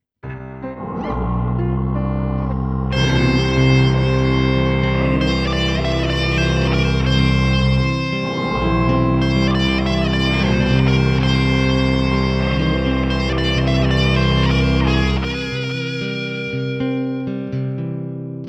Musique Lo-Fi